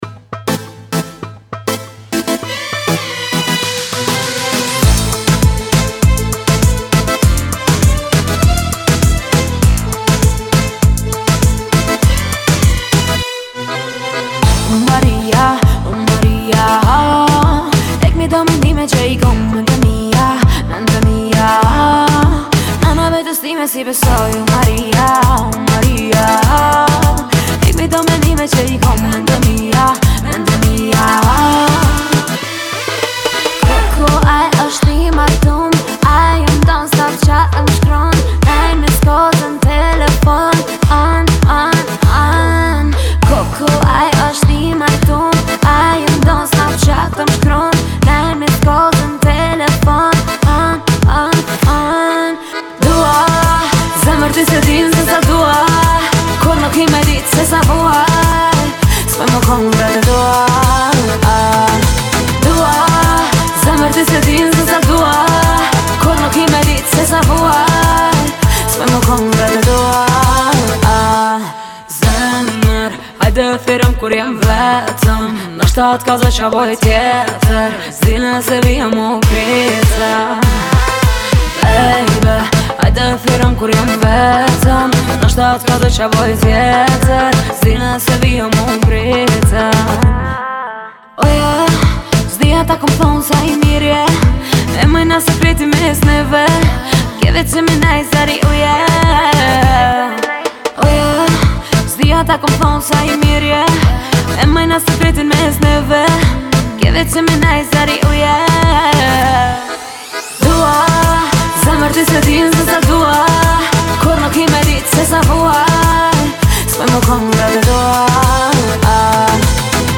ритмичными битами и запоминающимся хоровым моментом